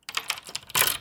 DoorUnlocking.ogg